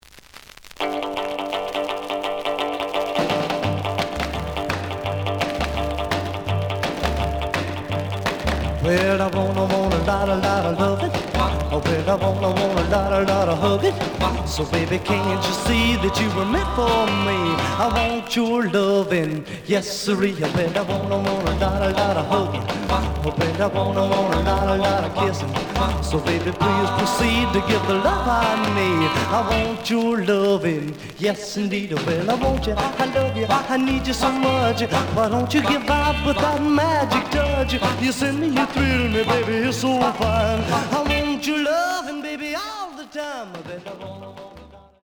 試聴は実際のレコードから録音しています。
●Genre: Rhythm And Blues / Rock 'n' Roll
●Record Grading: G+ (両面のラベルにダメージ。A面のラベルに書き込み。盤に若干の歪み。プレイOK。)